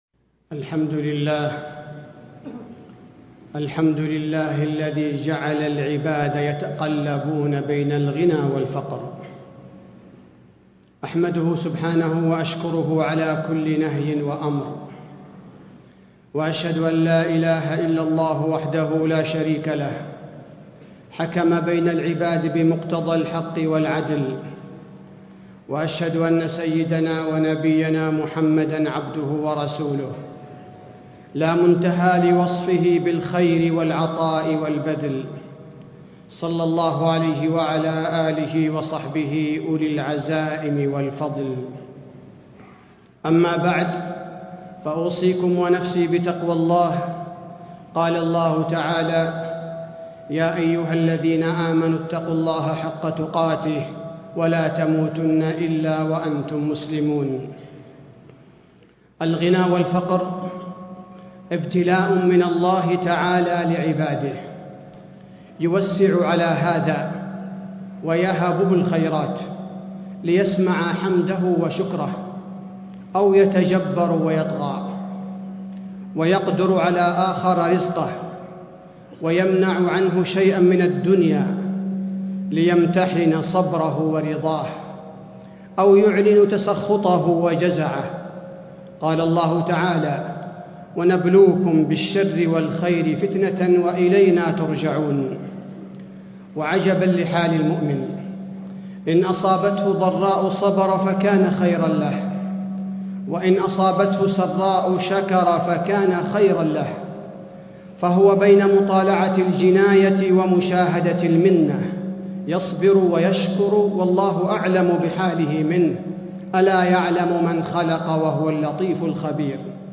تاريخ النشر ٣ رجب ١٤٣٥ هـ المكان: المسجد النبوي الشيخ: فضيلة الشيخ عبدالباري الثبيتي فضيلة الشيخ عبدالباري الثبيتي الحكمة الإلهية في الفقر والغنى